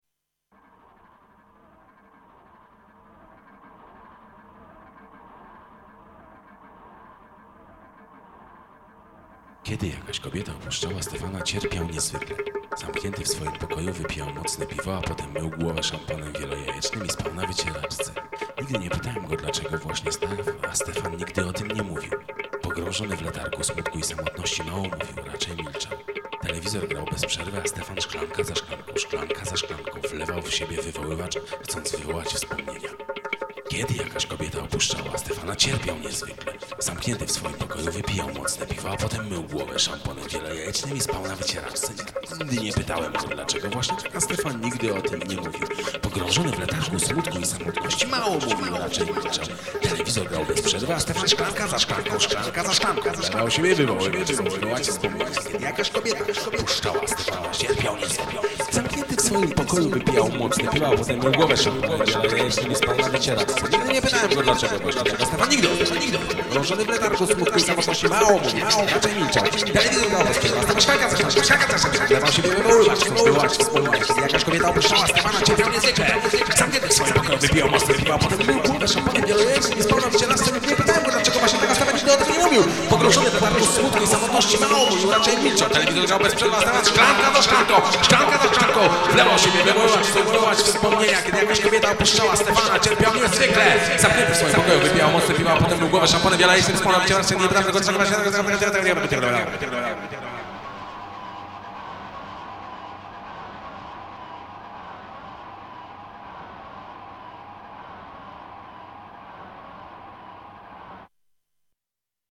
muzyka, instrumenty
teksty, głosy